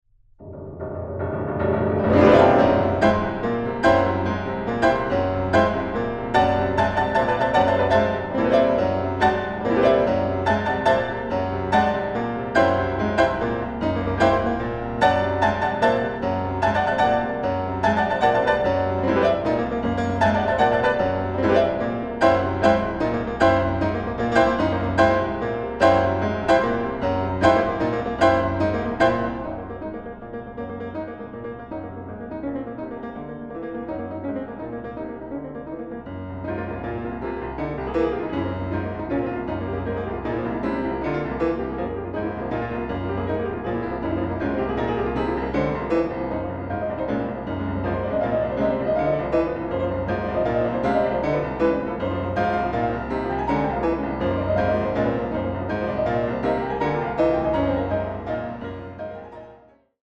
Piano
Recording: Mendelssohnsaal, Gewandhaus Leipzig